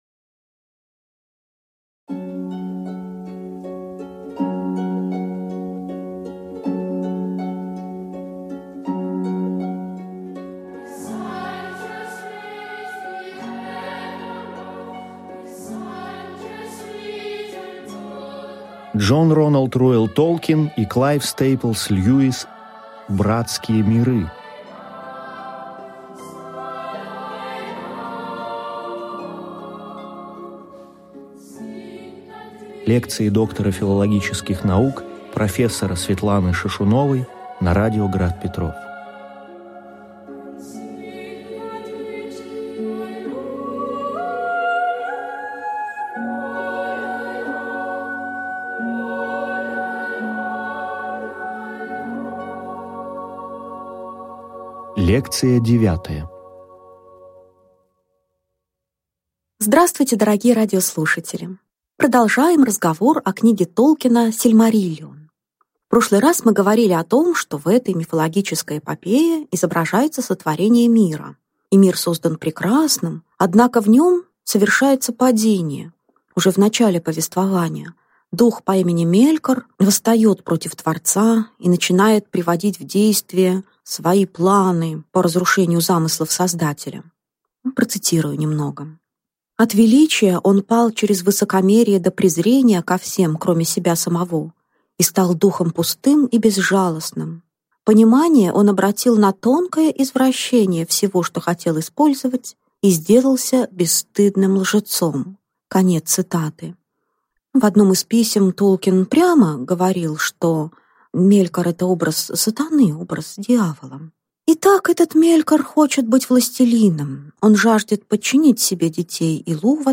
Аудиокнига Лекция 9. Дж.Р.Р.Толкин: значение образов эльфов | Библиотека аудиокниг